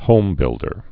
(hōmbĭldər)